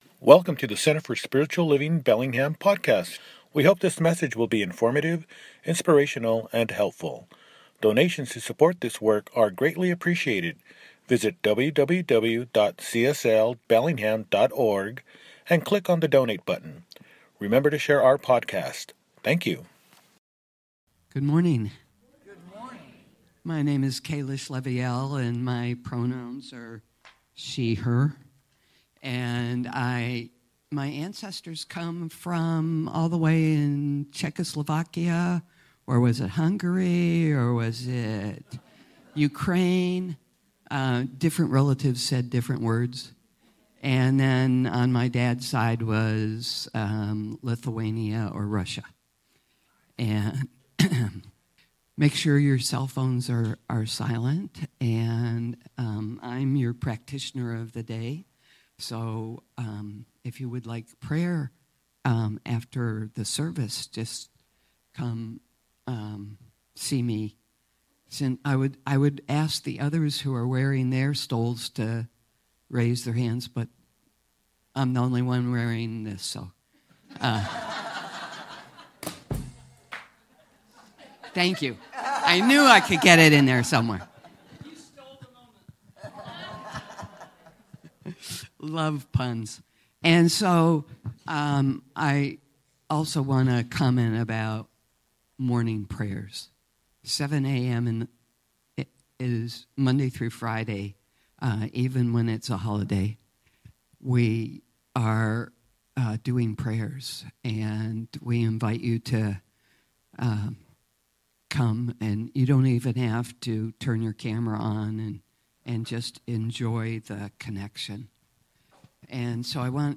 Release, Refresh & Renew – Celebration Service